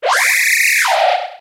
Cri de Bleuseille dans Pokémon HOME.